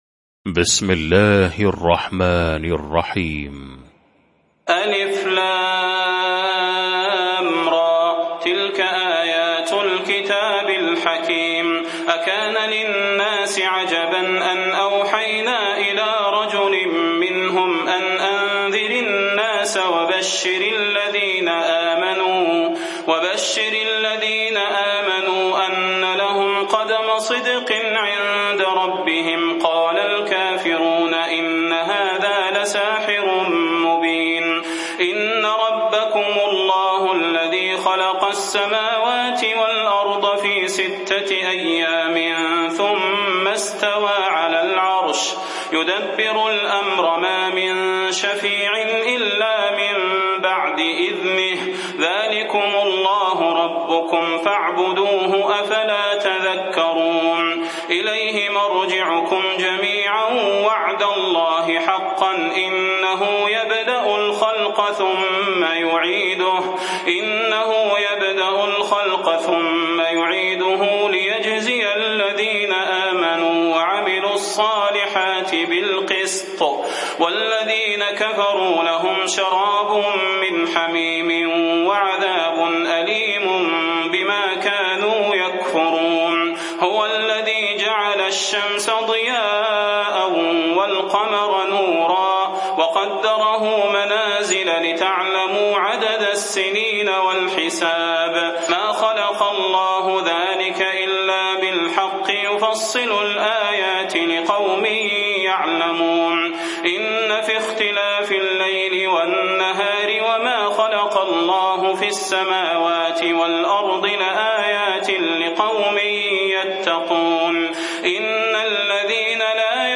المكان: المسجد النبوي الشيخ: فضيلة الشيخ د. صلاح بن محمد البدير فضيلة الشيخ د. صلاح بن محمد البدير يونس